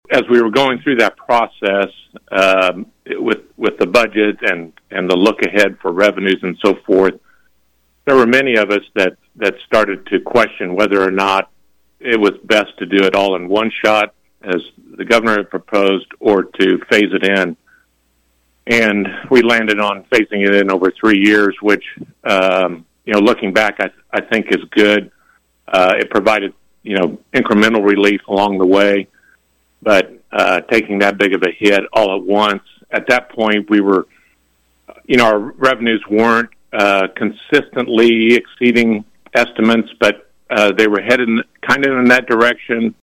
As part of KVOE’s Newsmaker segment Tuesday, Schreiber says lawmakers factored in the projected losses in sales tax dollars into upcoming budgets before the state started missing monthly revenue projections.